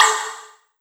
Snr Tekenfunk.wav